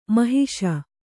♪ mahiṣa